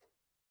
Tumba-Tap1_v1_rr1_Sum.wav